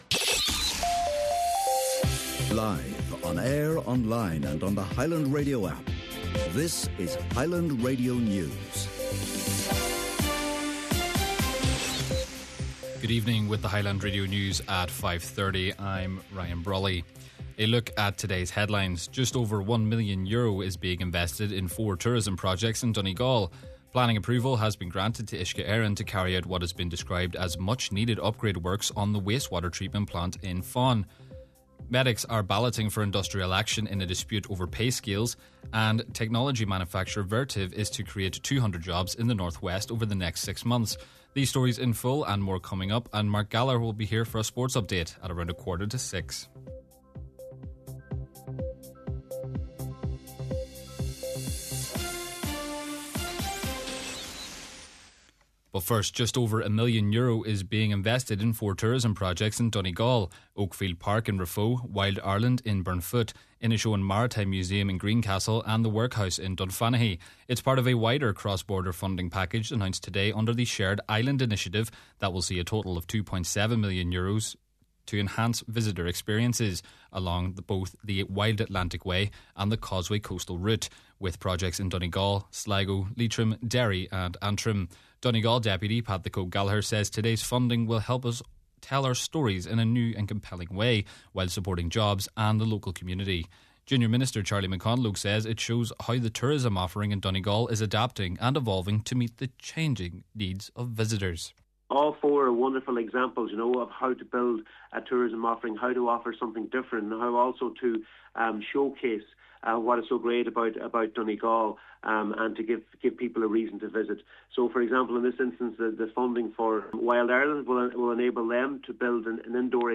Main Evening News, Sport and Obituary Notices – Friday, February 20th